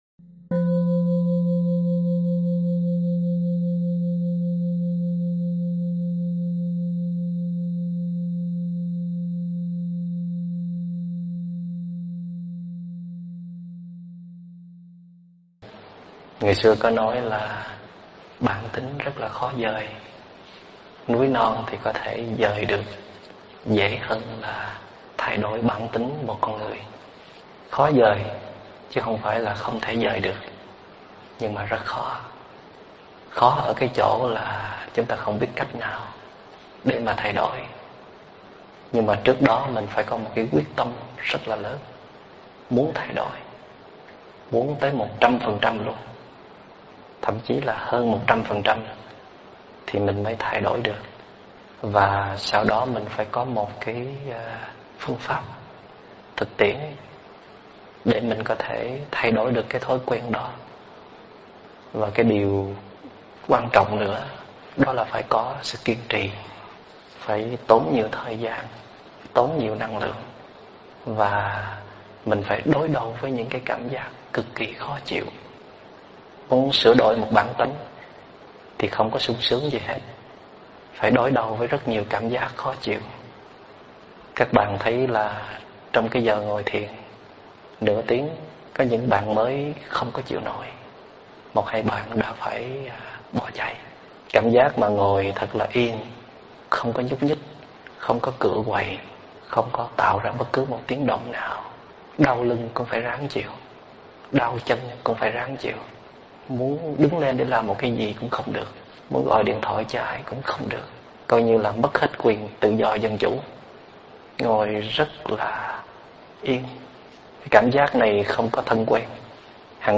Mời quý phật tử nghe mp3 thuyết pháp Cảm Xúc Cũng Chỉ Là Vô Thường do TS. Thích Minh Niệm giảng
Thích Minh Niệm giảng Mp3 Thuyết Pháp Thuyết pháp Thích Minh Niệm